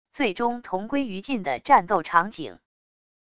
最终同归于尽的战斗场景wav音频